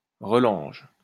Relanges (French pronunciation: [ʁəlɑ̃ʒ]